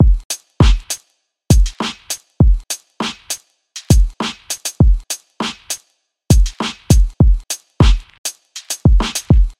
复古大鼓
描述：由lofi套件制成
Tag: 100 bpm Hip Hop Loops Drum Loops 1.62 MB wav Key : Unknown FL Studio